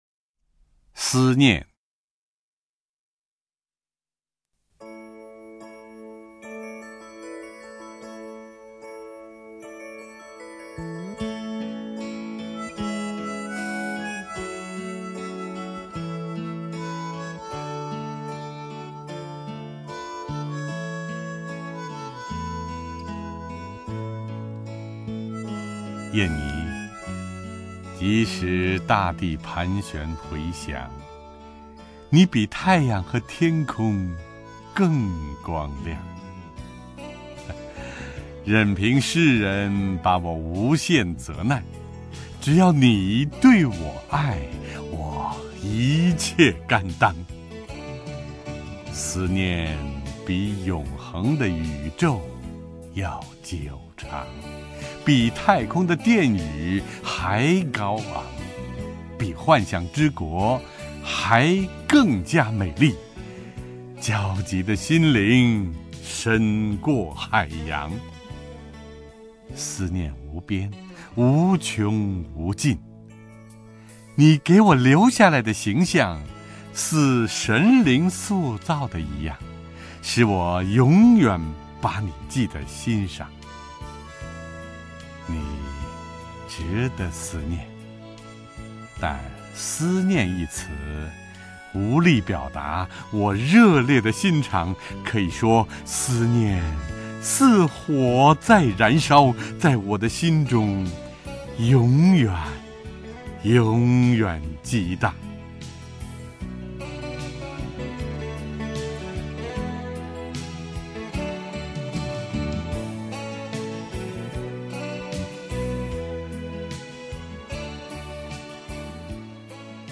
陈铎朗诵：《思念》(（德）卡尔·亨利希·马克思)
名家朗诵欣赏 陈铎 目录